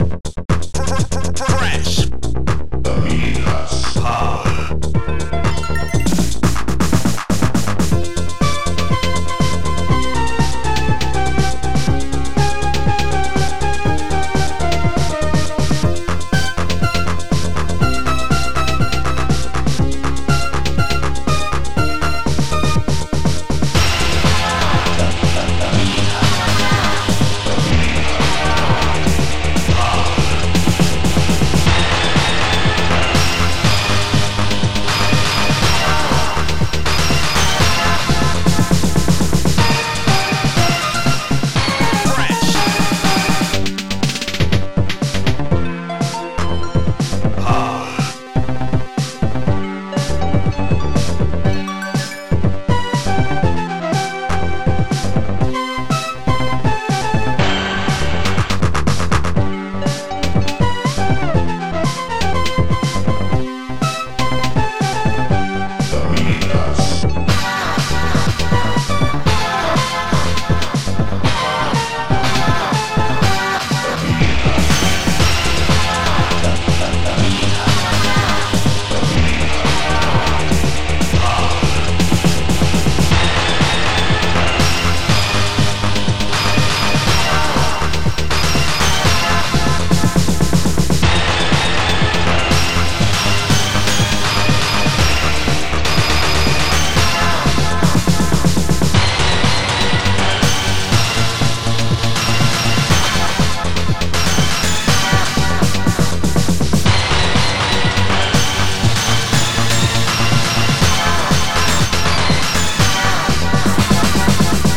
Synth
OctaMED Module